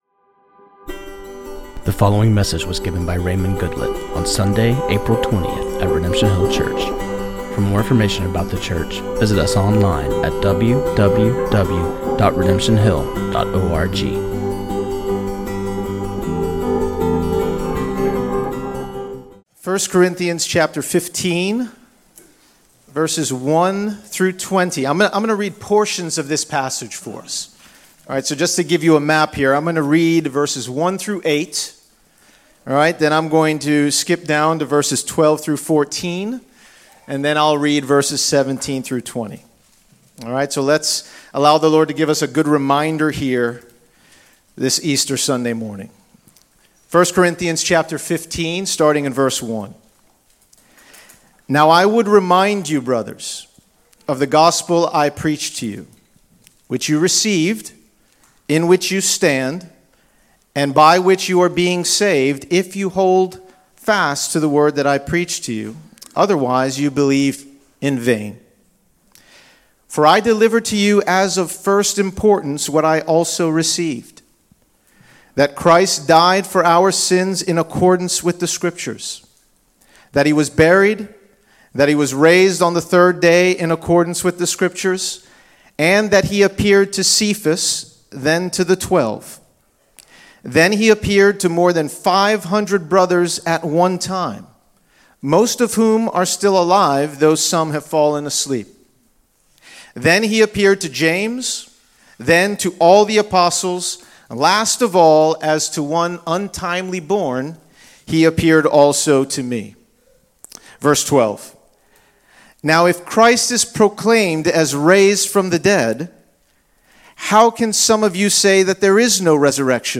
This sermon on 1 Corinthians 15:12-20